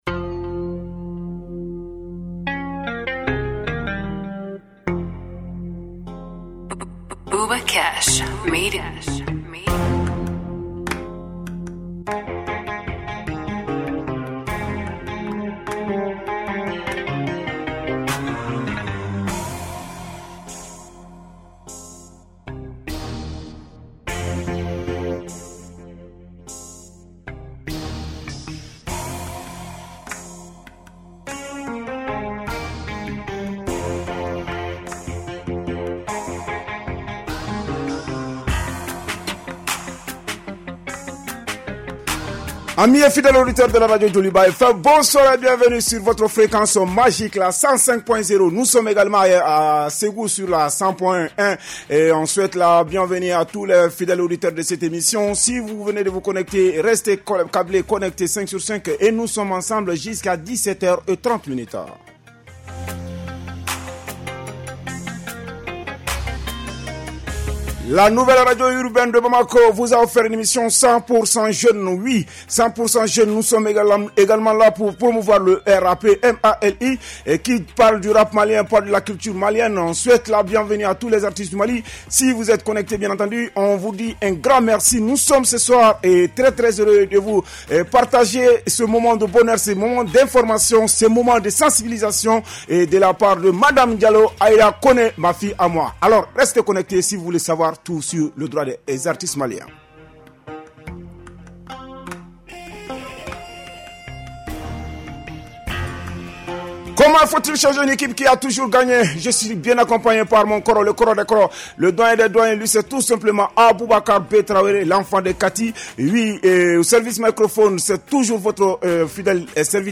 Un programme 100 % dédié à la scène rap et hip-hop du Mali avec des interviews exclusives, des freestyles et toute l’actualité croustillante de vos rappeurs préférés.